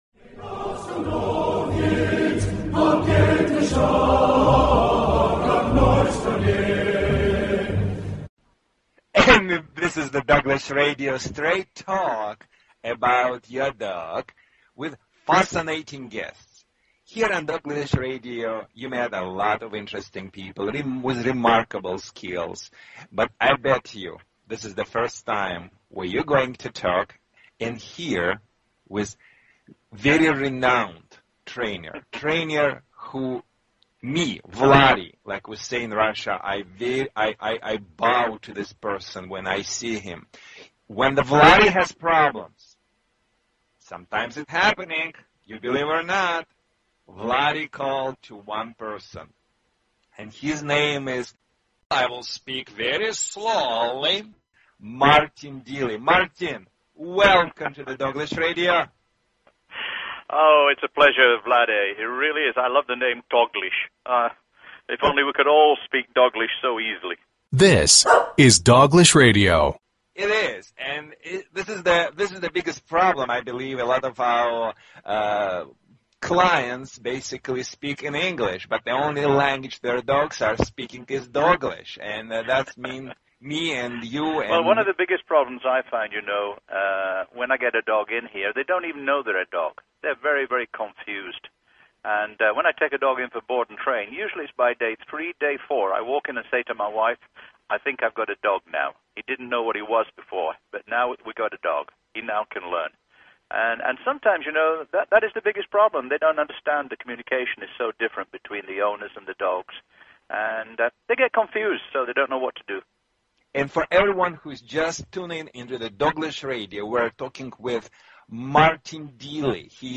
Talk Show Episode, Audio Podcast, Doglish_Radio and Courtesy of BBS Radio on , show guests , about , categorized as